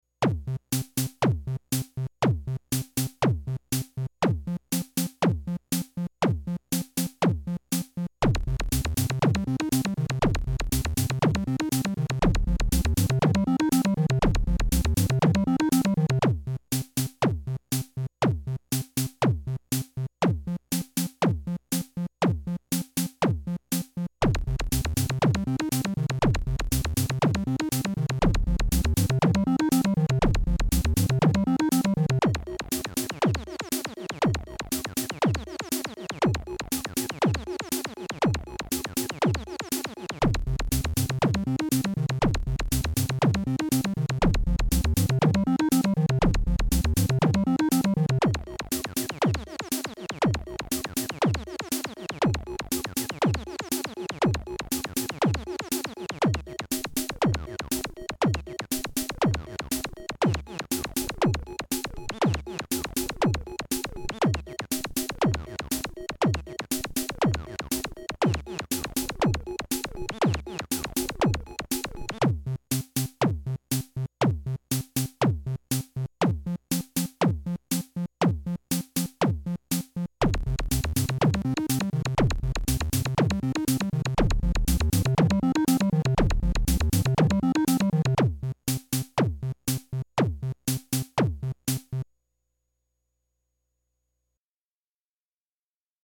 Nintendo DS Korg Synthesizer
Hey thought I'd post this little loop I made on the Korg DS-10 Synthesizer.
punchy! nice